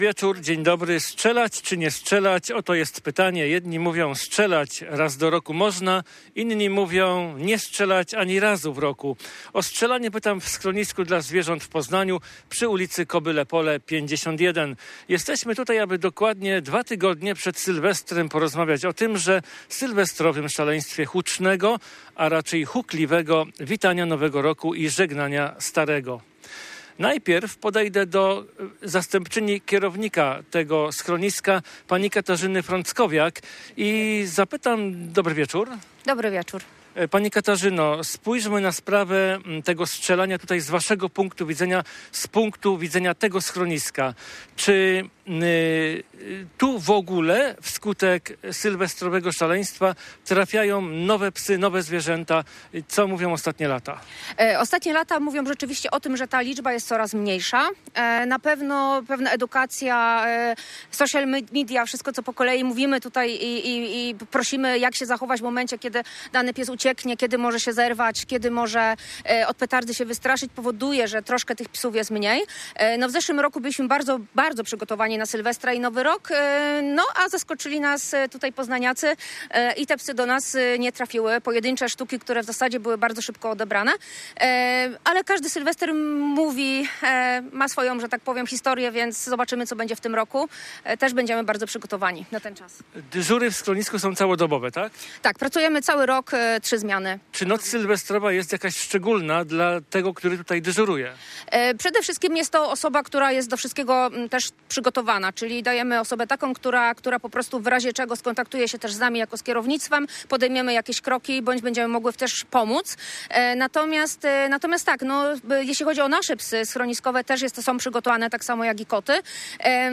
Audycję "W punkt" nadawaliśmy wprost ze schroniska dla zwierząt w Poznaniu. Poświęciliśmy ją zbliżającemu się Sylwestrowi i kontrowersjom wokół strzelania petardami i fajerwerkami.